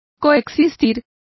Complete with pronunciation of the translation of coexist.